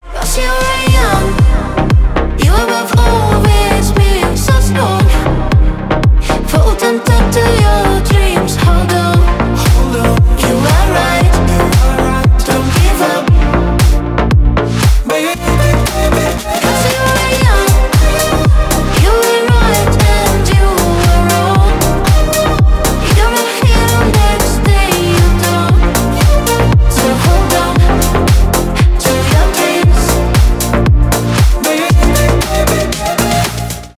танцевальные
retromix
клубные